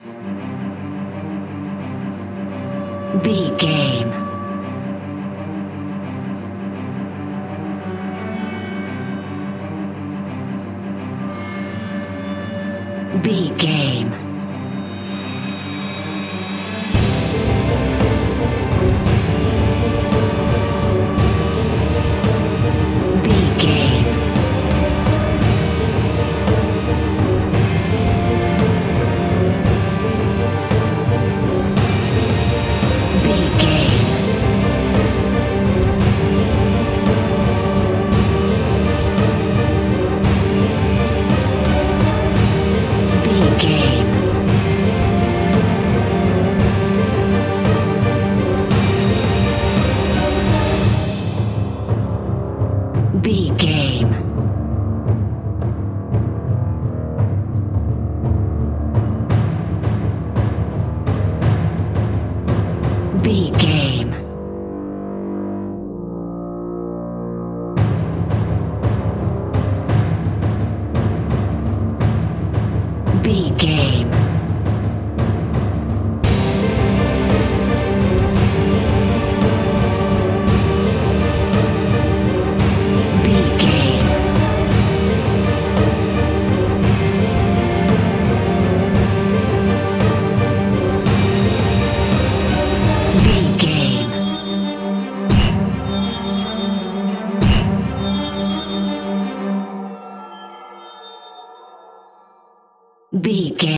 Powerful Epic Music Cue.
In-crescendo
Aeolian/Minor
Fast
ominous
suspense
strings
brass
percussion